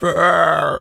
pig_scream_short_05.wav